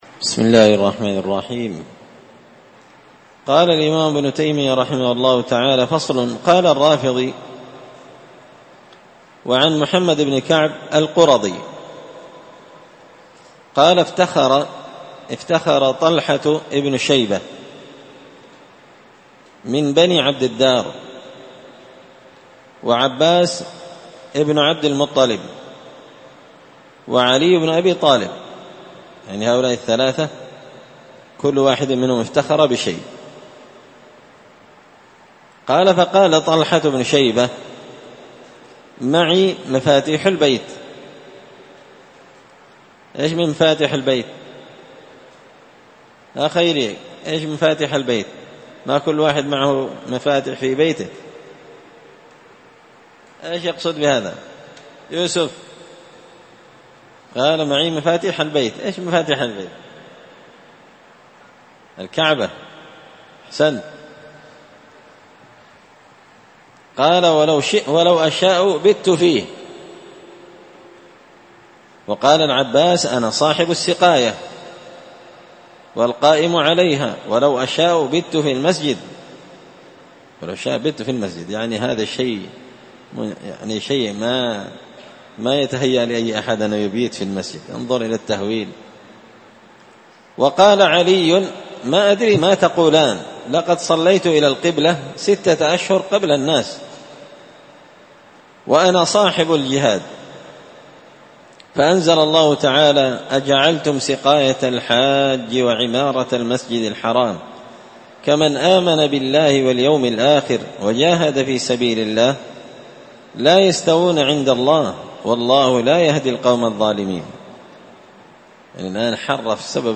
84الدرس-الرابع-والثمانون-من-مختصر-منهاج-السنة-فصل-في-خطأ-استدلال-الرافضي-بآية-أجعلتم-سقاية-الحاج.mp3